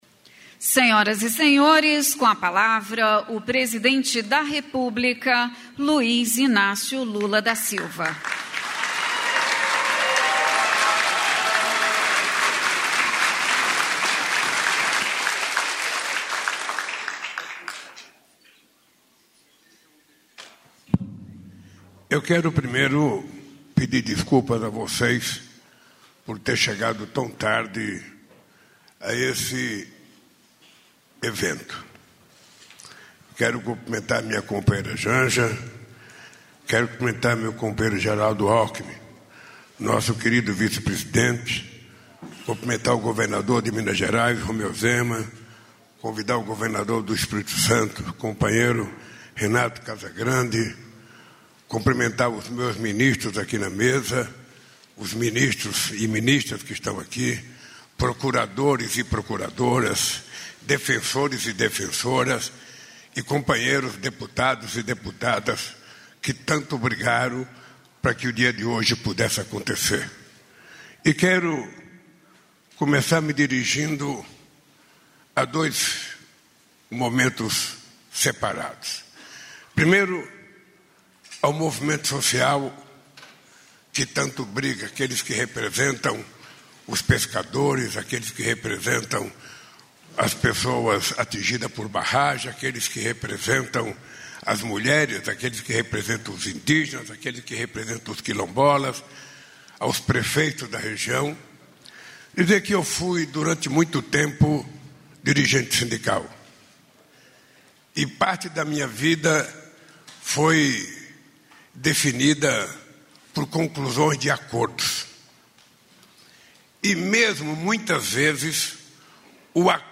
Íntegra das falas de abertura e encerramento do presidente Luiz Inácio Lula da Silva na reunião com ministros e governadores no Palácio do Planalto, em Brasília, nesta quinta-feira (31).